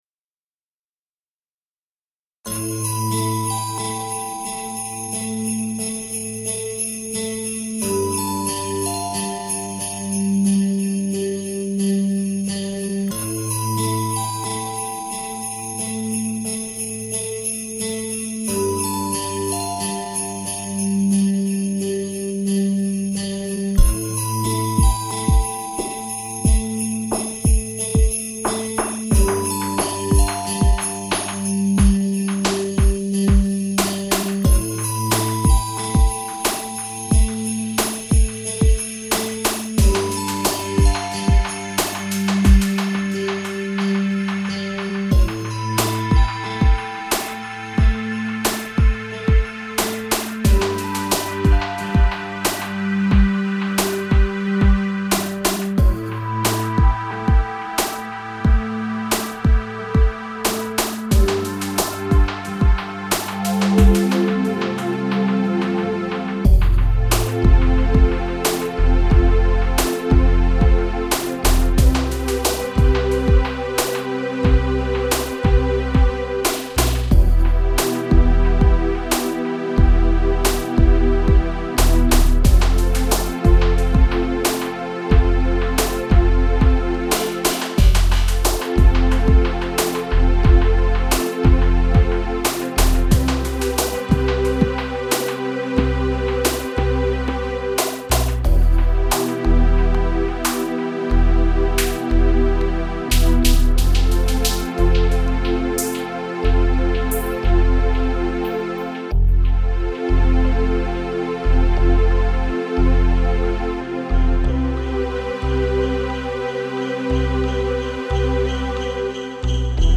Ja, es ist kitschig!